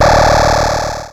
RetroGamesSoundFX / Alert / Alert05.wav
Alert05.wav